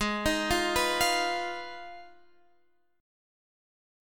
G#m6 chord